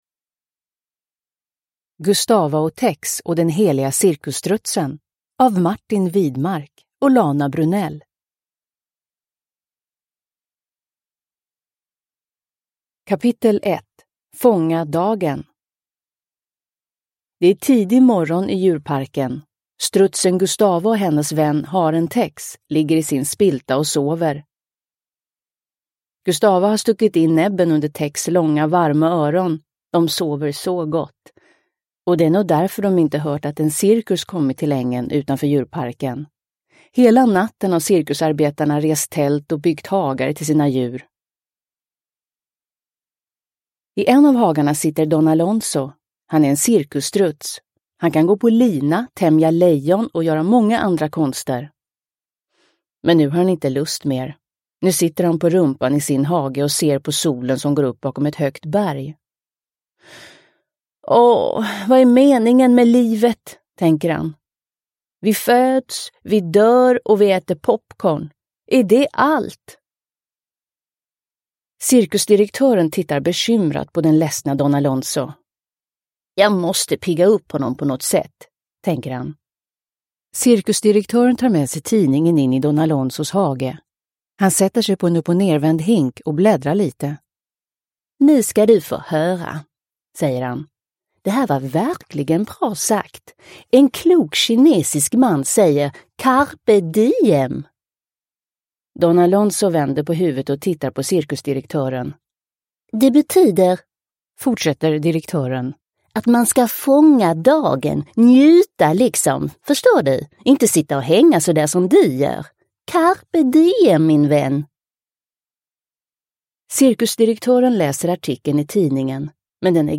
Gustava & Tex och den heliga cirkusstrutsen – Ljudbok – Laddas ner